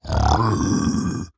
Minecraft Version Minecraft Version snapshot Latest Release | Latest Snapshot snapshot / assets / minecraft / sounds / mob / piglin_brute / angry2.ogg Compare With Compare With Latest Release | Latest Snapshot
angry2.ogg